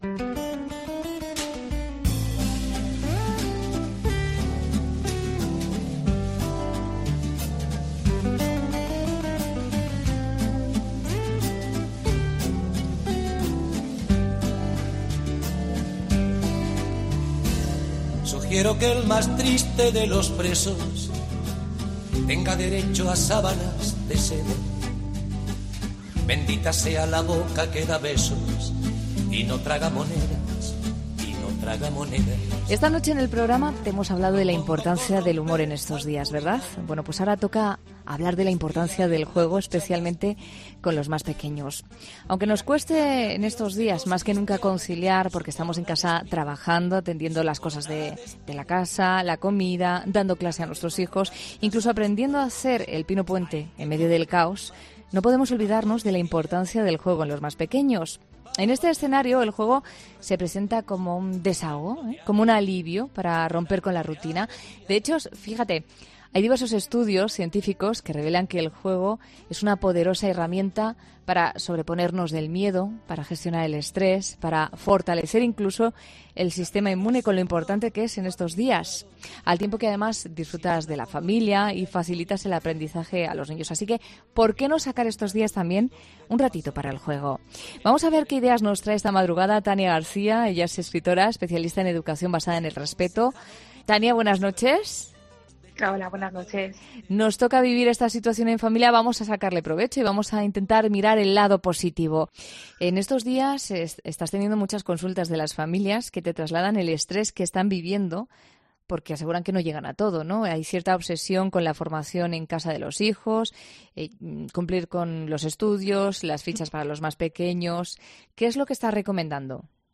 'La Noche' de COPE es un programa que mira la actualidad de cada día con ojos curiosos e inquietos, y en el que el tema principal de cada día, aquel del que todo el mundo habla, se ve desde un punto de vista distinto.